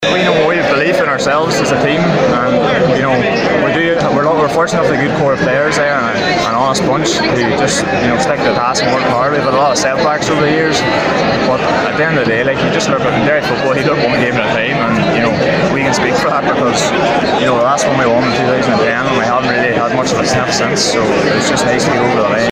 spoke after the game